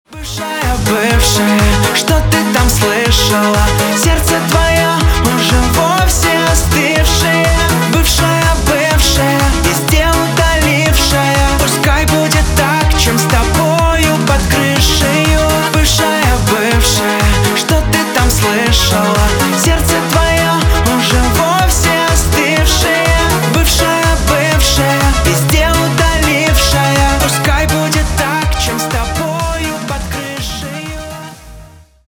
на русском грустные на бывшую